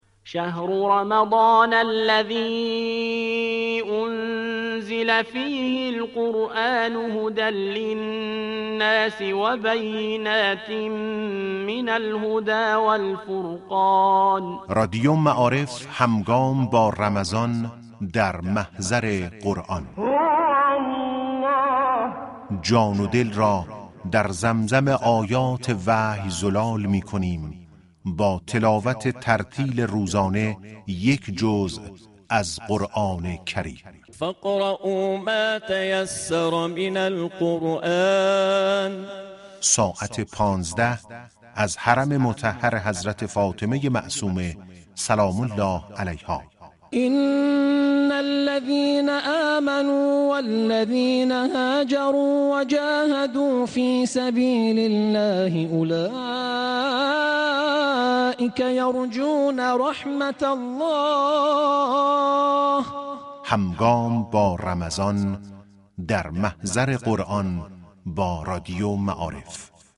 پخش زنده جزء خوانی قرآن كریم از رادیو معارف
رادیو معارف همزمان با آغاز ماه مهمانی خدا اقدام به پخش زنده جزء خوانی از حرم مطهر حضرت معصومه (س) می كند